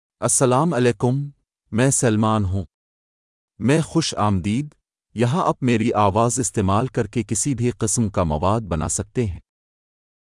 Salman — Male Urdu (India) AI Voice | TTS, Voice Cloning & Video | Verbatik AI
MaleUrdu (India)
SalmanMale Urdu AI voice
Salman is a male AI voice for Urdu (India).
Voice sample
Listen to Salman's male Urdu voice.
Salman delivers clear pronunciation with authentic India Urdu intonation, making your content sound professionally produced.